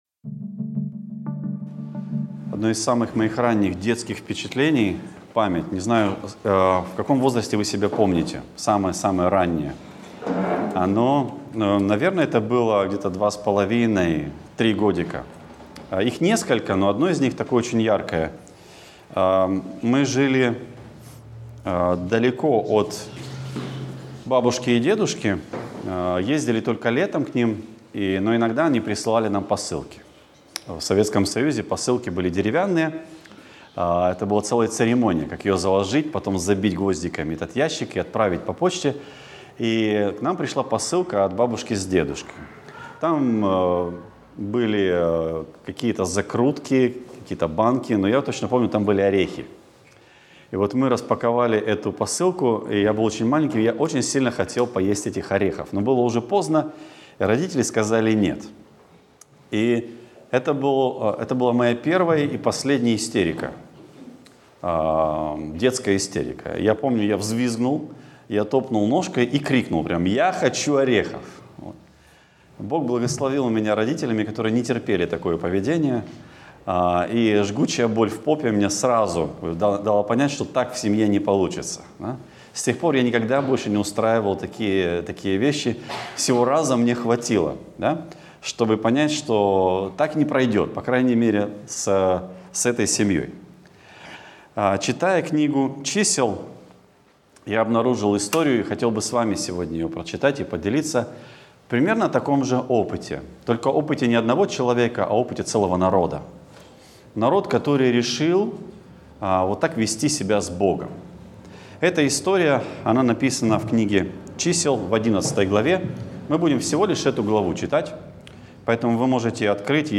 Гробы прихоти Проповедник